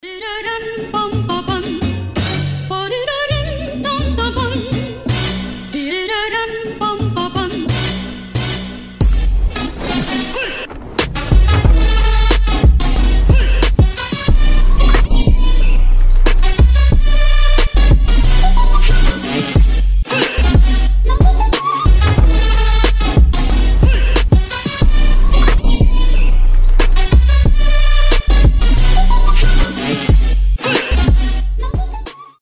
• Качество: 320, Stereo
мощные басы
Trap
Rap